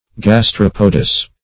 Gastropodous \Gas*trop"o*dous\, a.